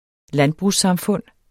Udtale [ ˈlanbʁus- ]